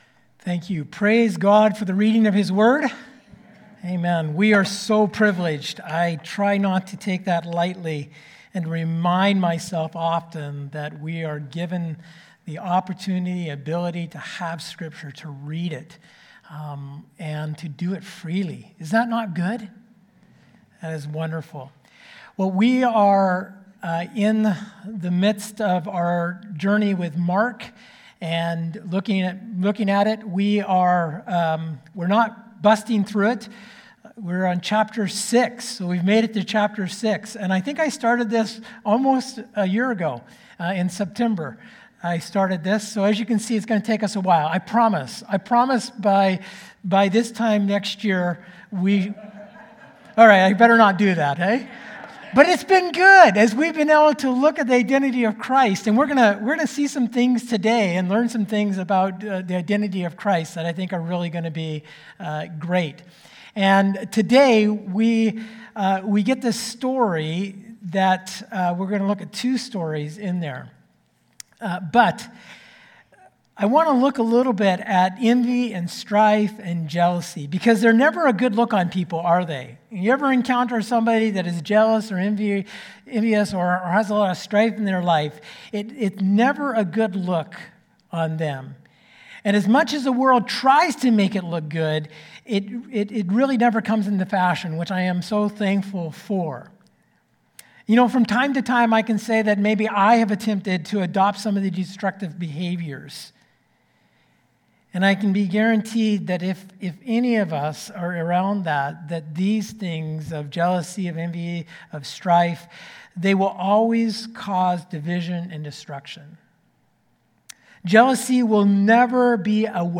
Sermons | Oceanview Community Church